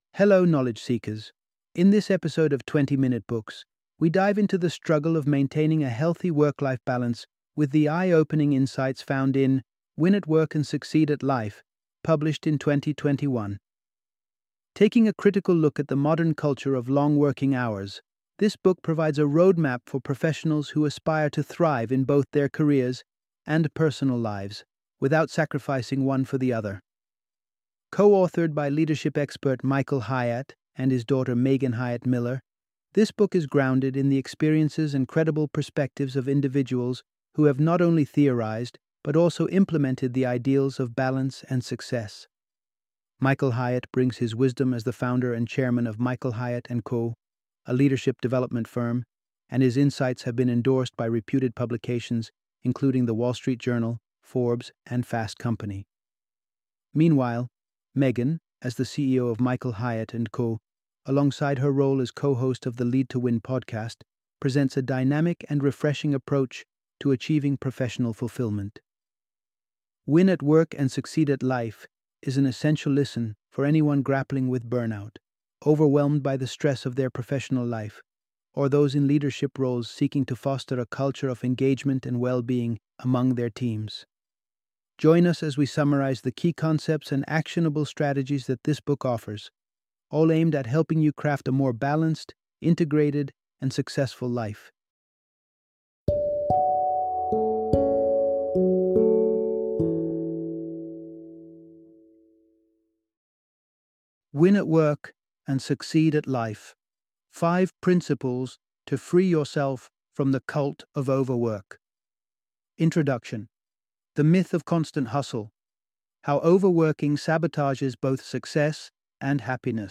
Win at Work and Succeed at Life - Audiobook Summary